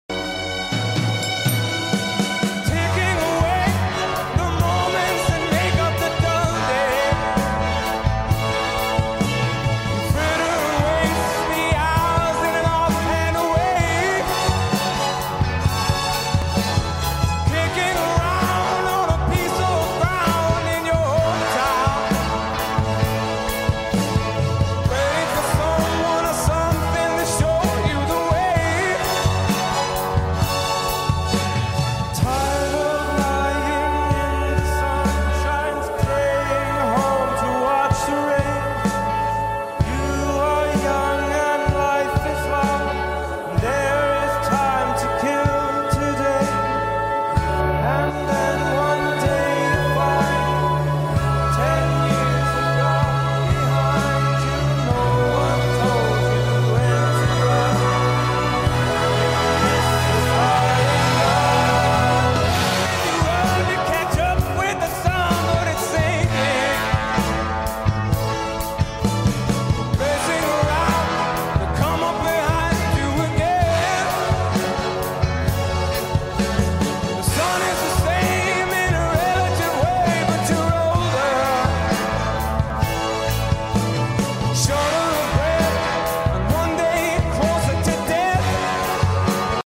arrangements for full orchestra, band and singers